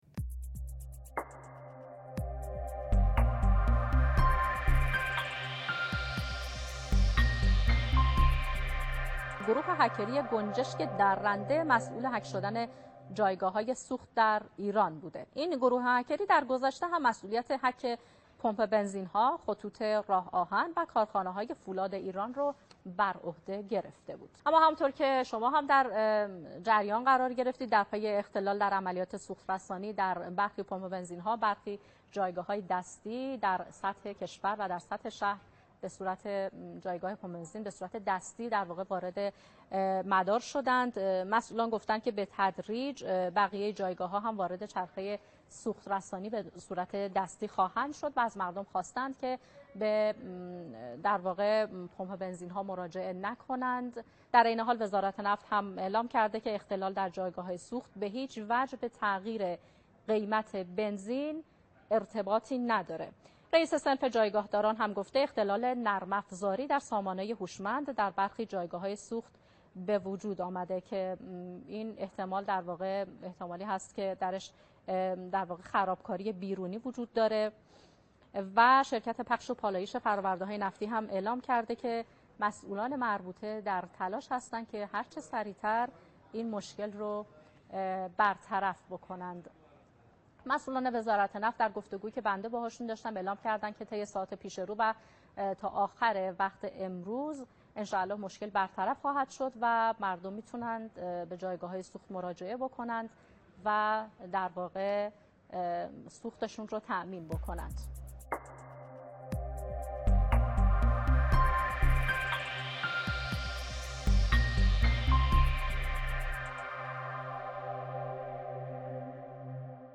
دانلود صوت خبر اختلال در پمپ بنزین‌های کشور